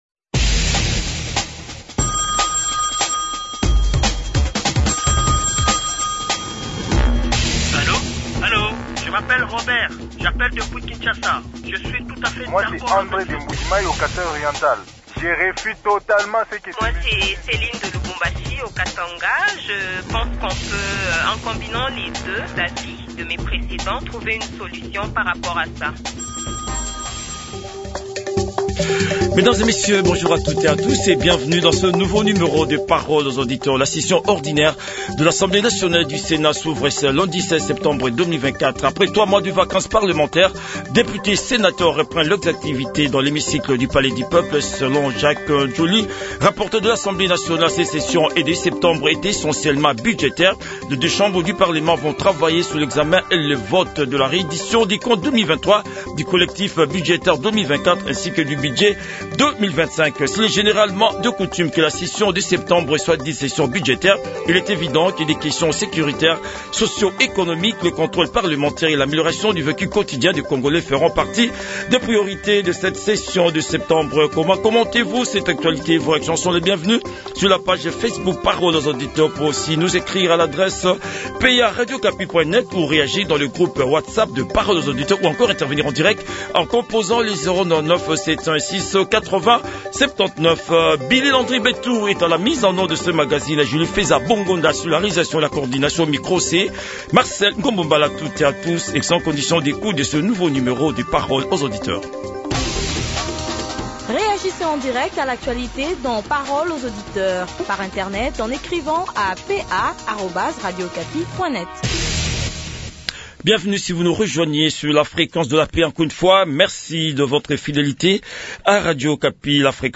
Les auditeurs ont débattu avec l’Honorable Carly Nzanzu, député national élu du territoire de Beni dans le Nord Kivu